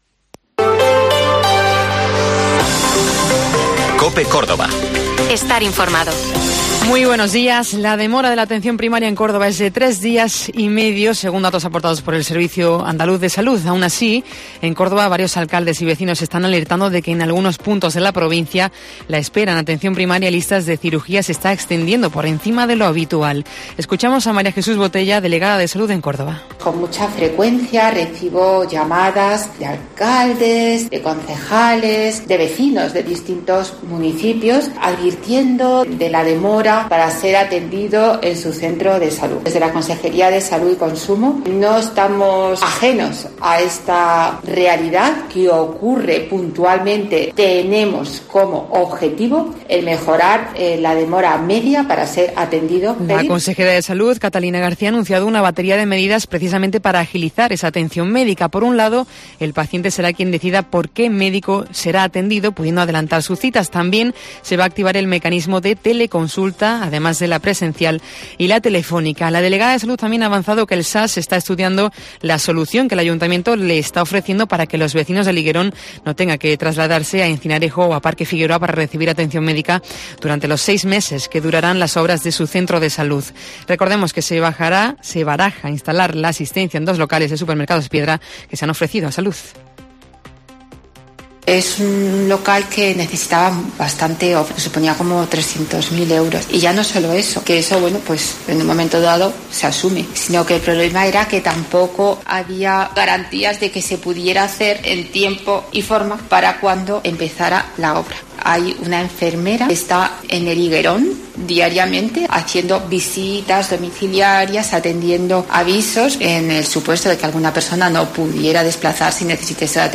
Informativo Herrera en COPE Córdoba